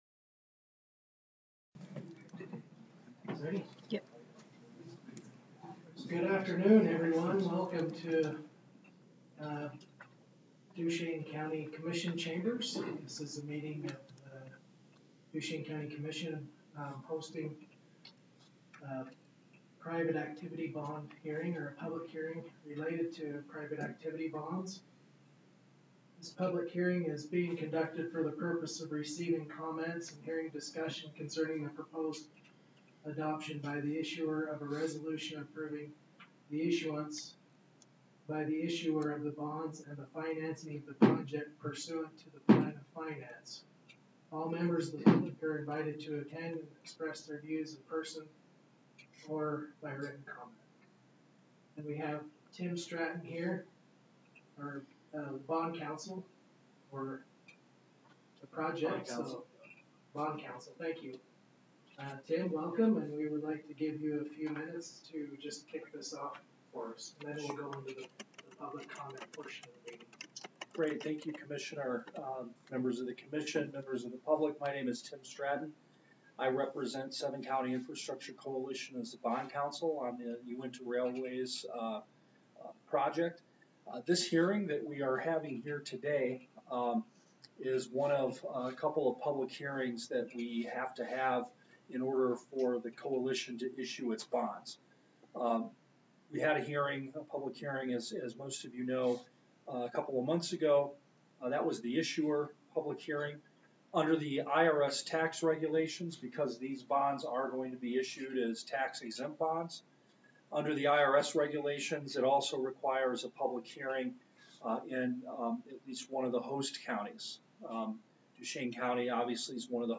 Public Hearing